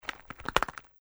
怪异的脚步声.mp3
通用动作/01人物/01移动状态/怪异的脚步声.mp3
• 声道 立體聲 (2ch)